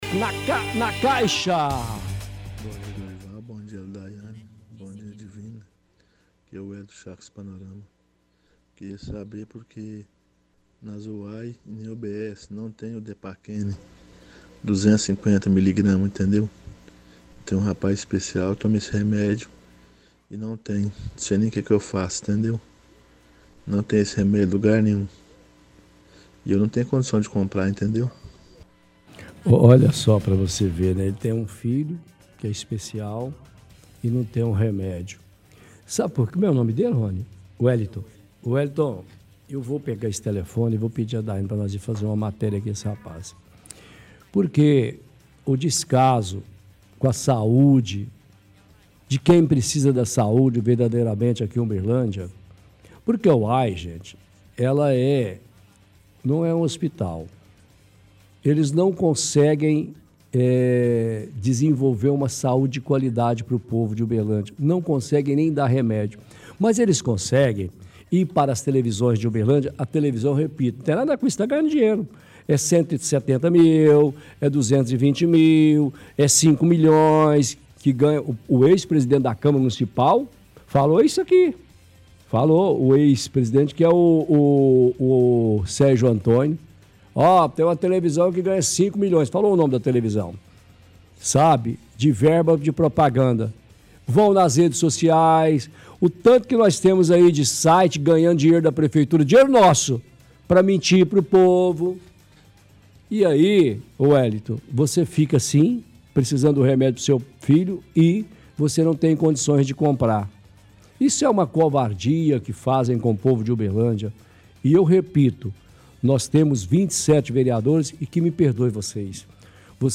– Ouvinte reclama de falta de medicação (depakene 250 miligramas) nas unidades de saúde.